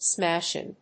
音節smásh・ing 発音記号・読み方
/ˈsmæʃɪŋ(米国英語)/